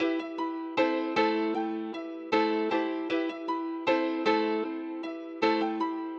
Cubeatz型失谐钢琴
Tag: 155 bpm Trap Loops Piano Loops 1.04 MB wav Key : E FL Studio